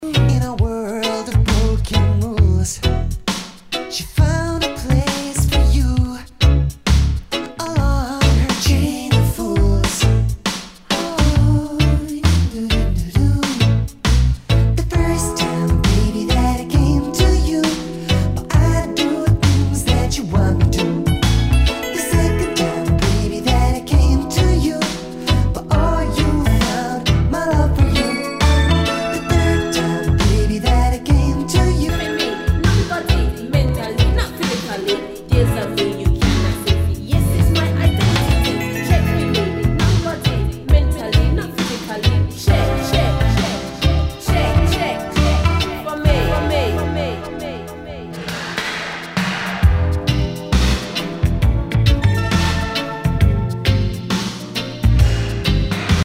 Nu- Jazz/BREAK BEATS
ラガ・シンセ・ポップ / ダウンテンポ！